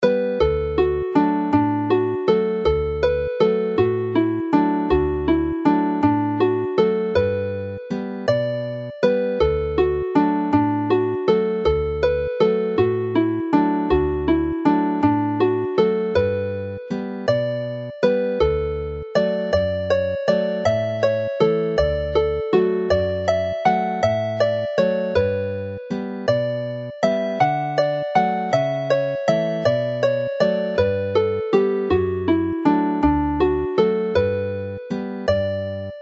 Chwarae'r alaw'n araf
Play the tune slowly